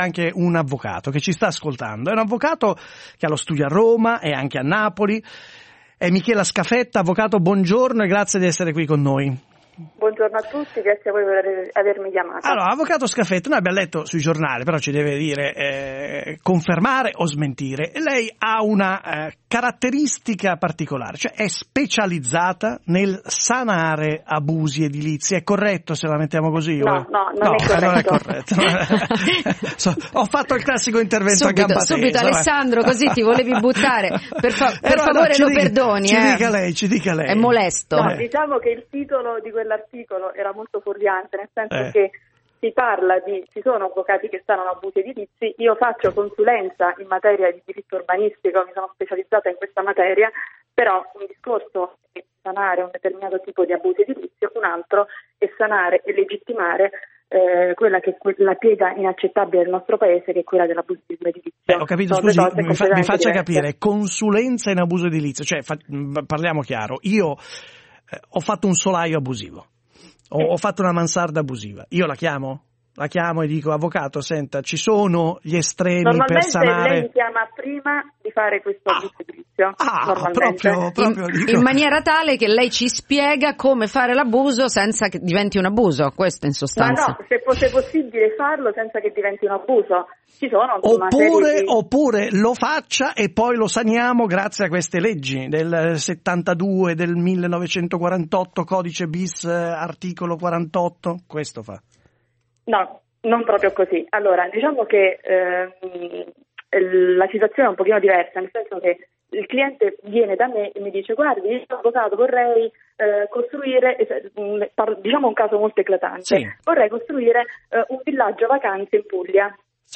abusi-edilizi-radio24.mp3